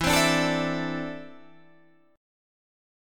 E7sus4#5 chord